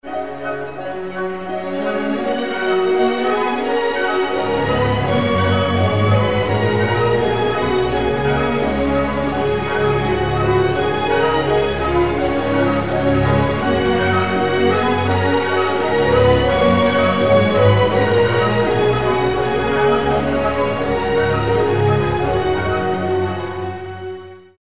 sweeping